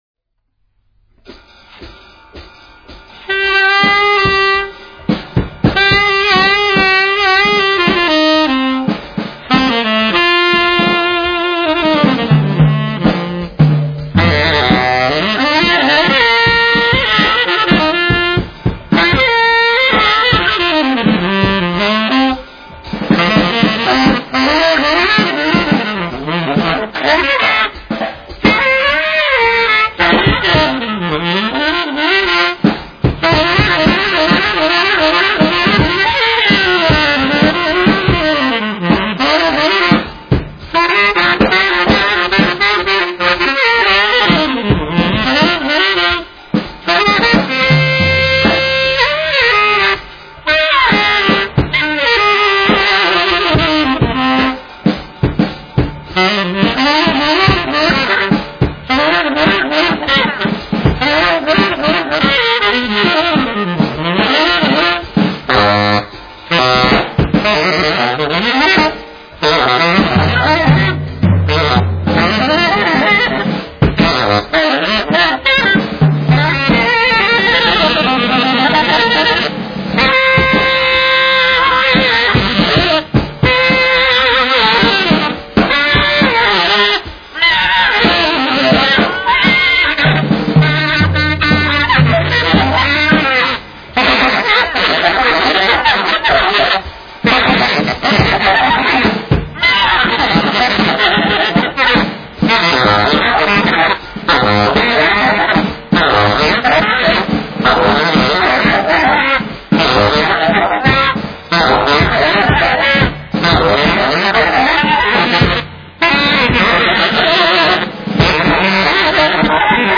tenor sax
drums.